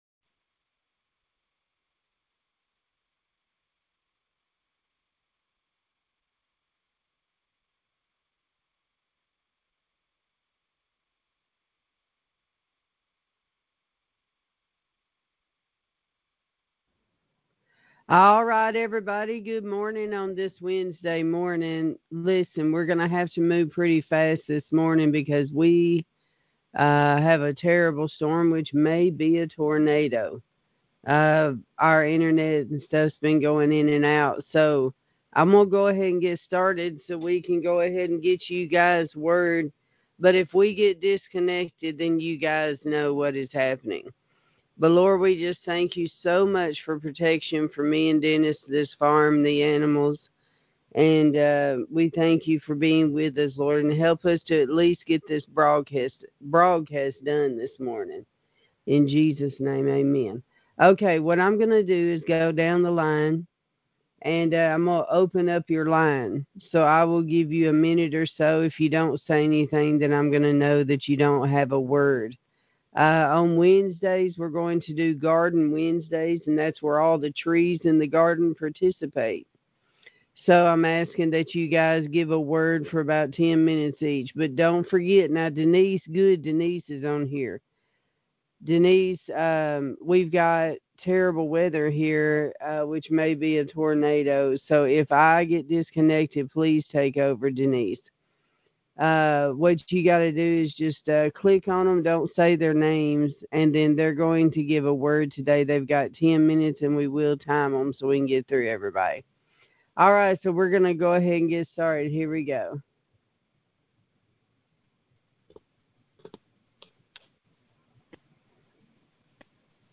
On Wednesdays we open the prayer lines for the Trees in the Garden to impart their own seeds! We may hear testimonies, preaching, encouragement, etc. This is amazing!